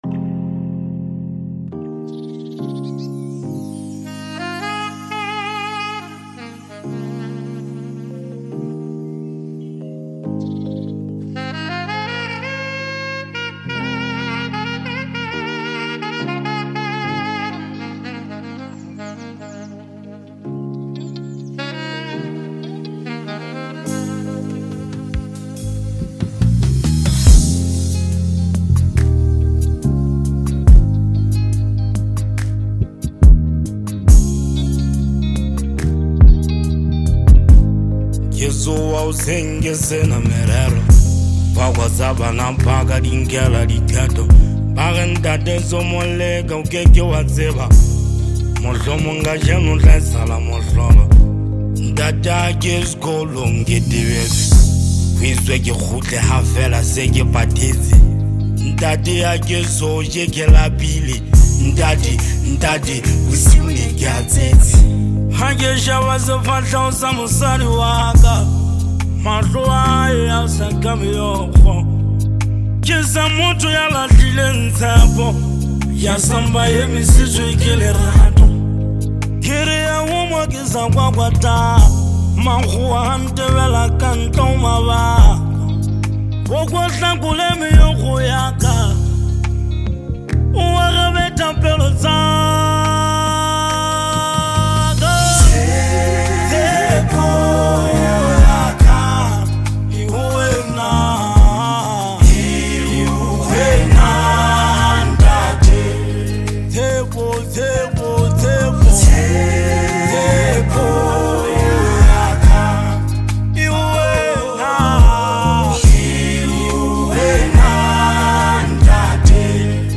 Award winning singer-songwriter